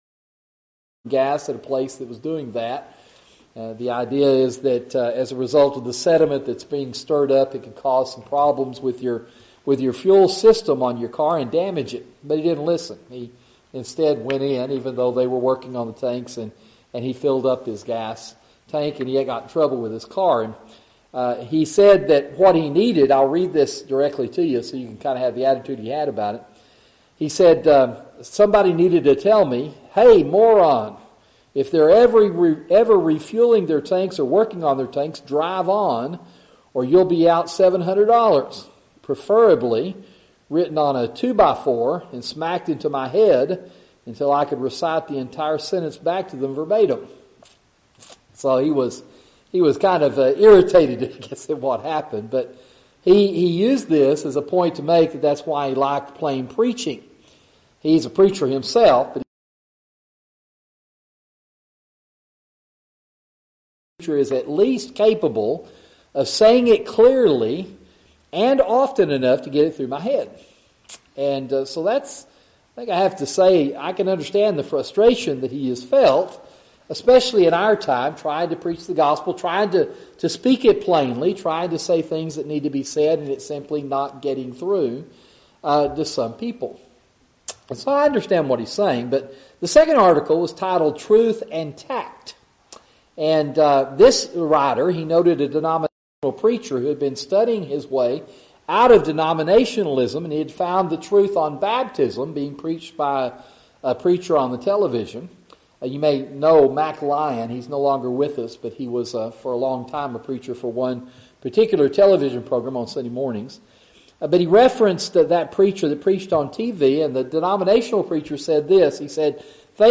Sermon: Gospel Preaching